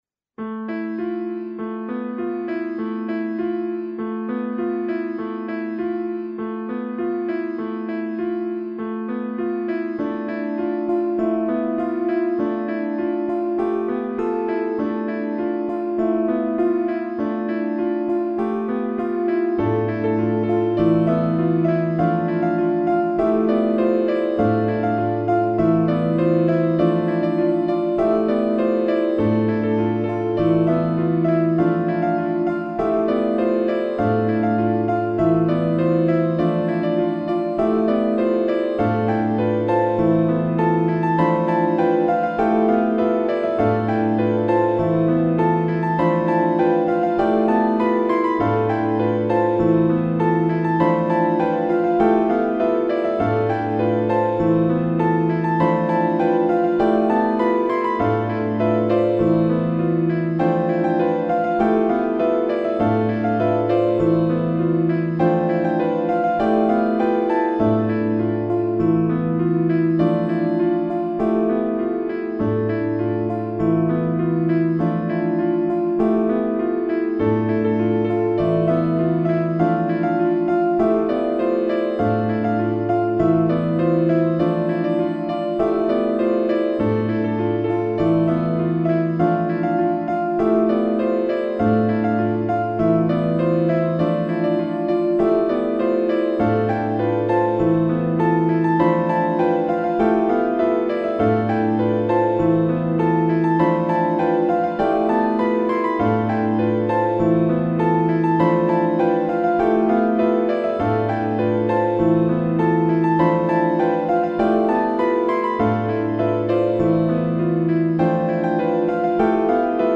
AEFABFE